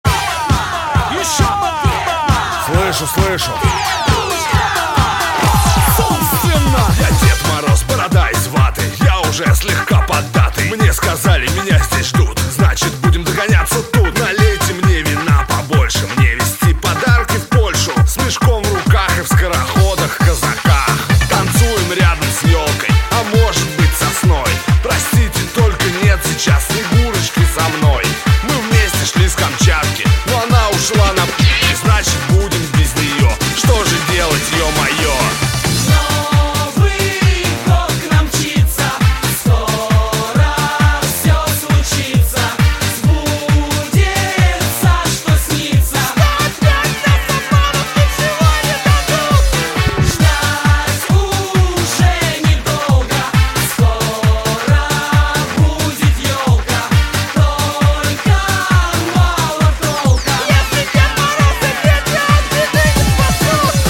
Новогодние рингтоны